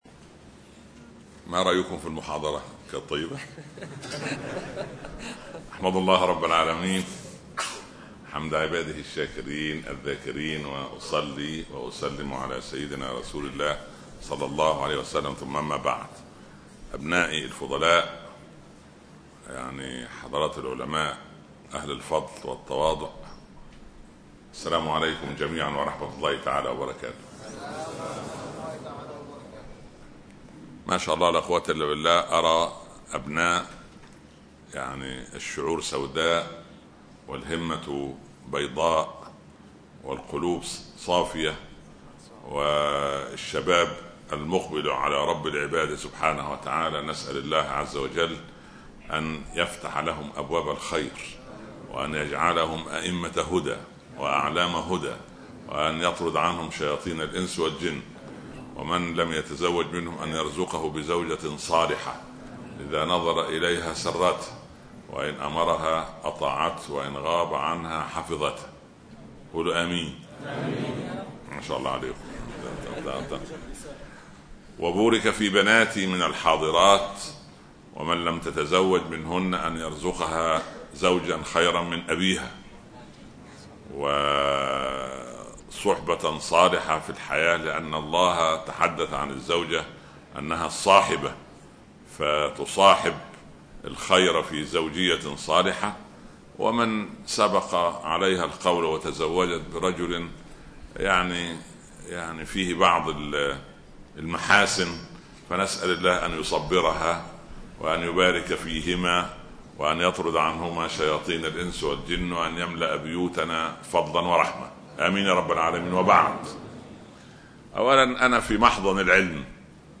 الحياة الطيبة.. محاضرة للدكتور عمر عبد الكافي في مدرسة الحياة الدولية – عرمون.